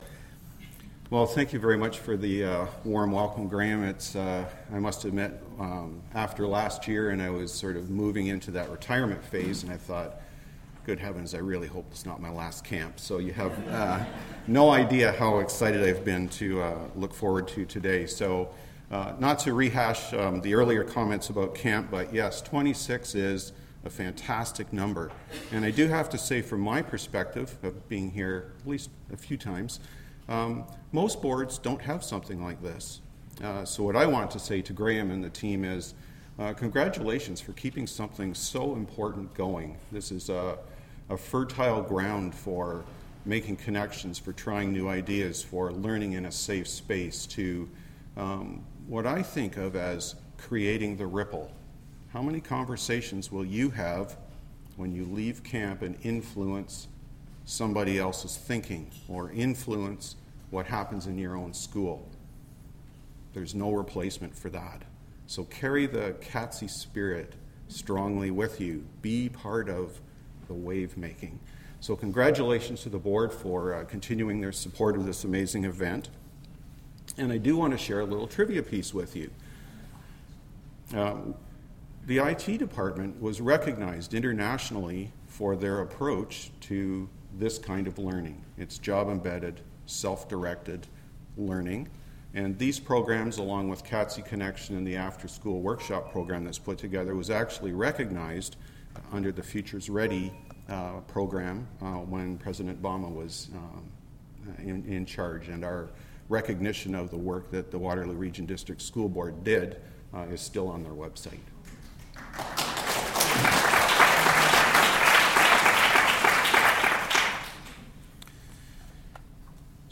Audio recording of presentation:
catccampkeynote2017.mp3